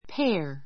péə r ペ ア